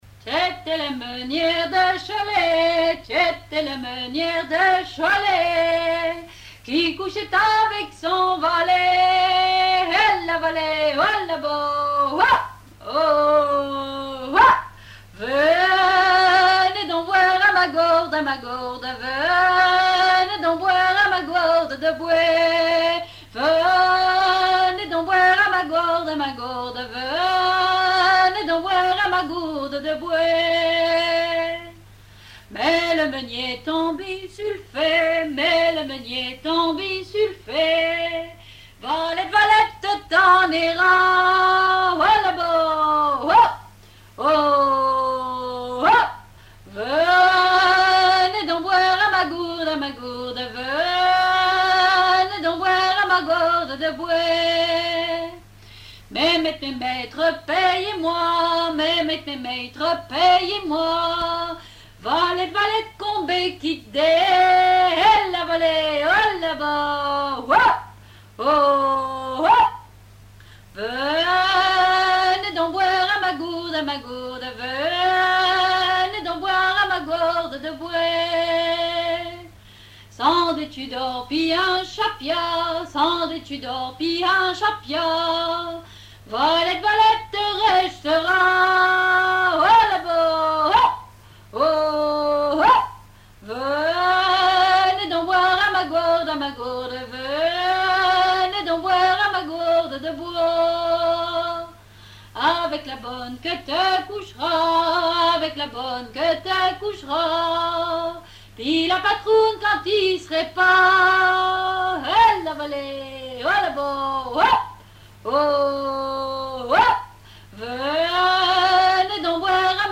Genre laisse
répertoire de chansons traditionnelles
Pièce musicale inédite